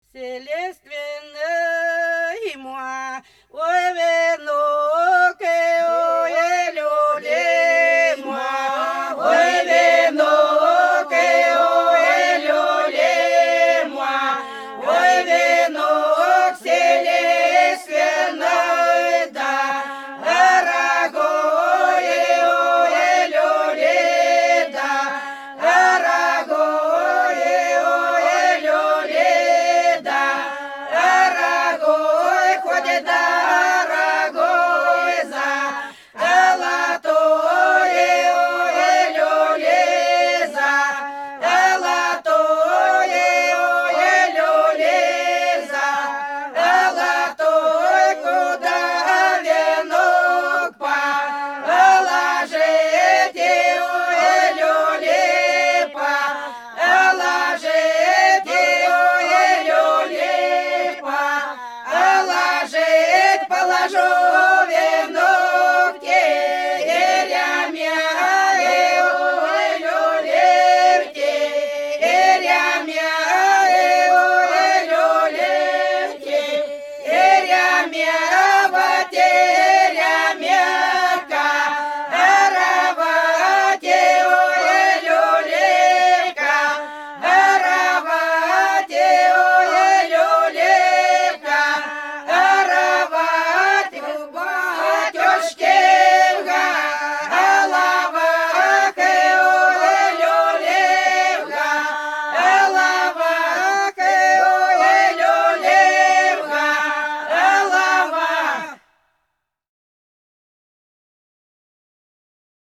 Пролетели все наши года Вселиственый мой венок – троицкая (Фольклорный ансамбль села Иловка Белгородской области)
03_Вселиственый_мой_венок_–_троицкая.mp3